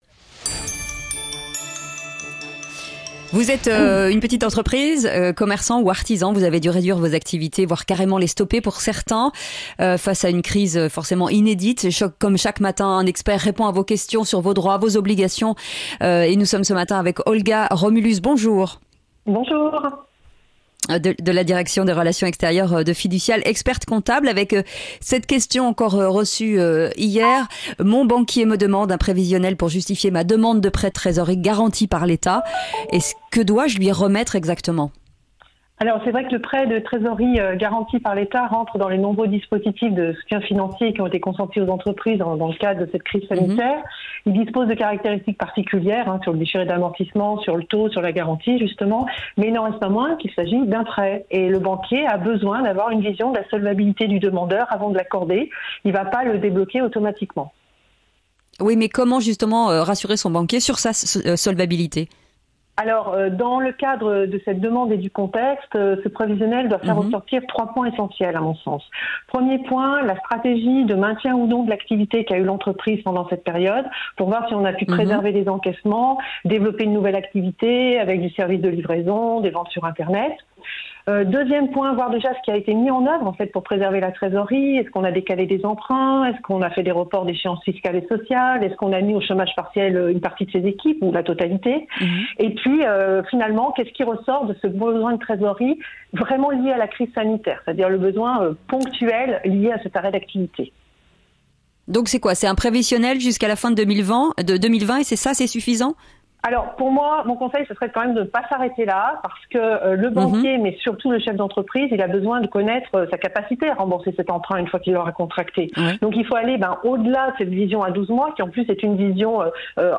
La minute pratique - A 9 heures, chaque jour dans le Grand Matin Sud Radio, des spécialistes Fiducial vous répondent.